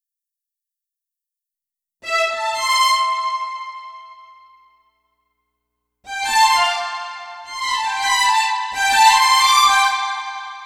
Strings 01.wav